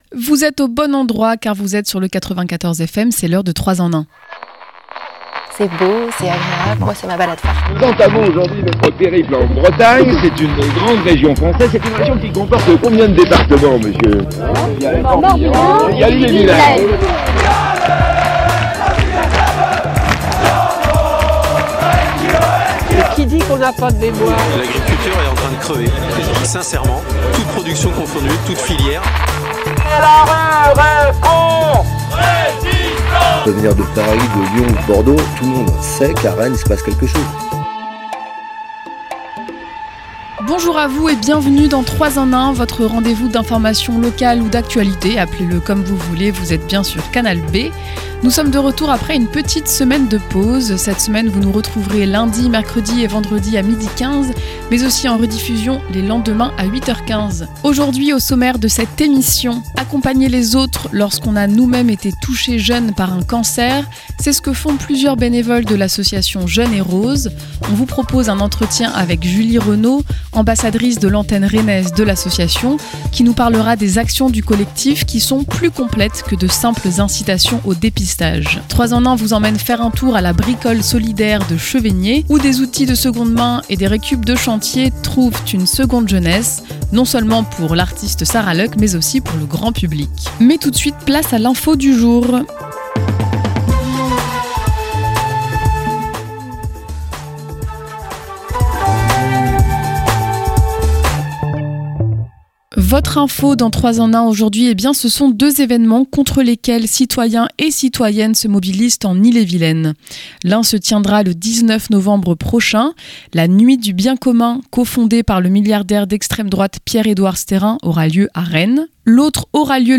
L'interview
Le reportage